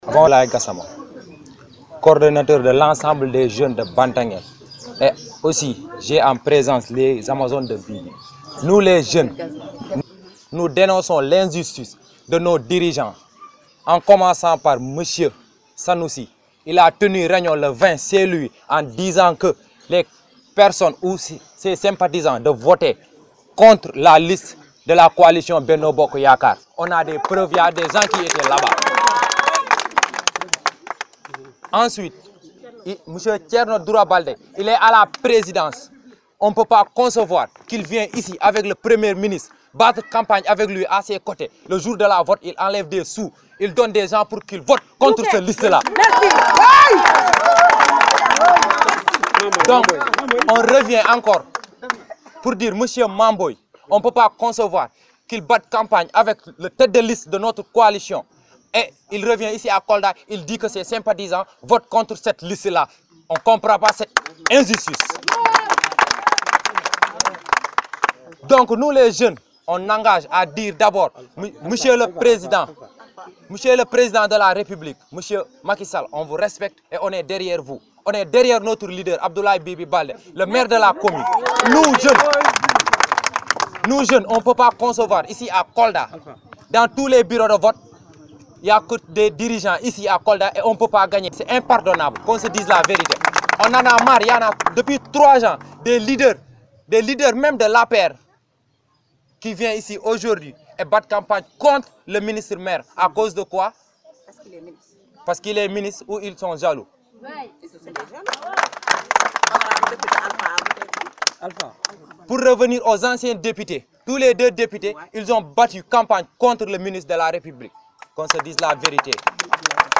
Ils ont tenu un point de presse pour imputer la responsabilité de cette défaite à des responsables de l’APR qui, selon eux, ont battu campagne contre la coalition présidentielle.